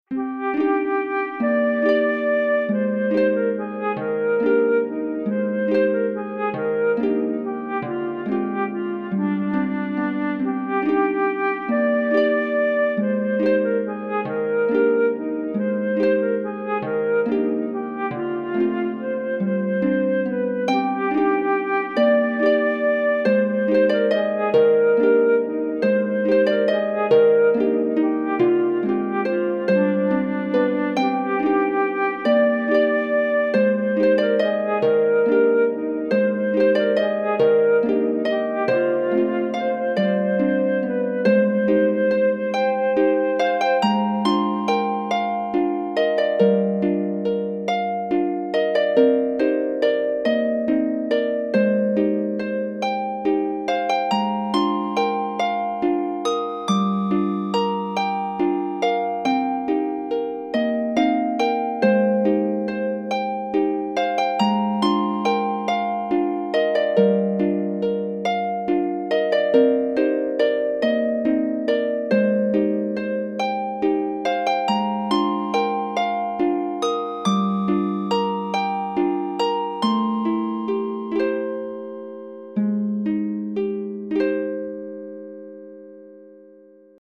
切なく寂しいメロディー　ハープとフルートのシンプルver.